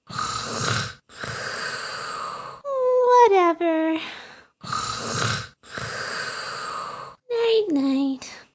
daisy_snoring3.ogg